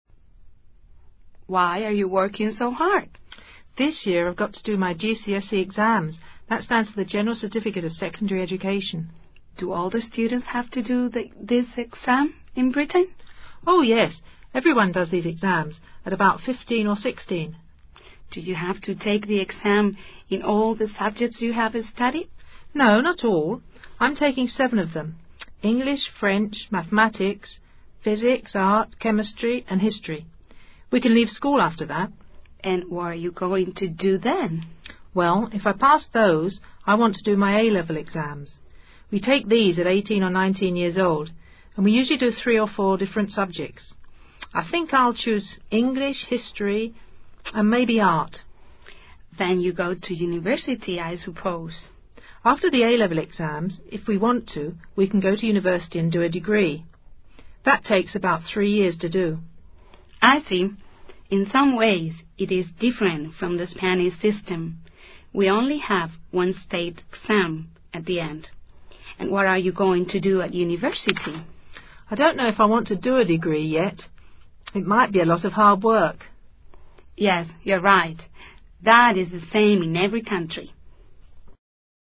Diálogo entre dos estudiantes sobre los exámenes escolares en Gran Bretaña.